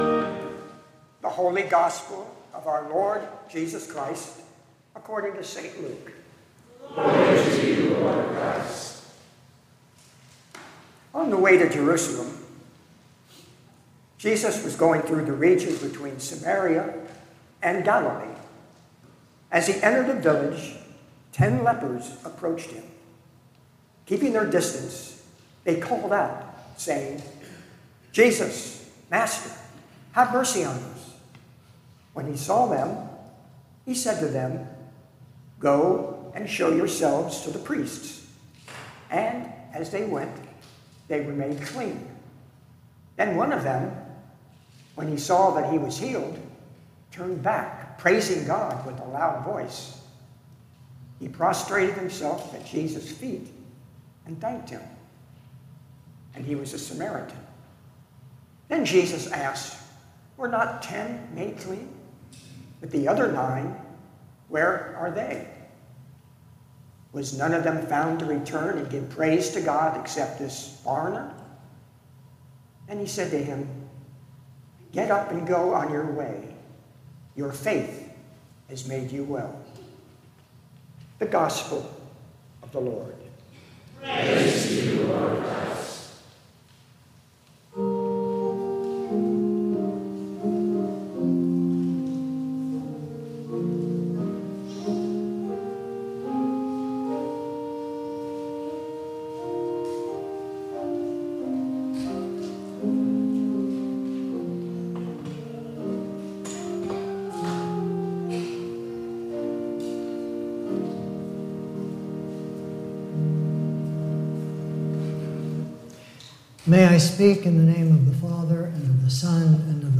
Latest Sermons & Livestreams